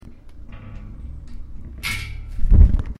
gate